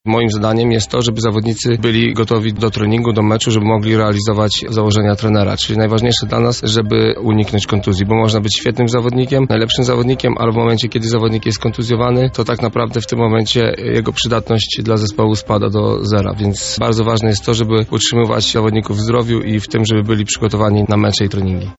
Opublikowano w Aktualności, Audycje, Poranna Rozmowa Radia Centrum, Sport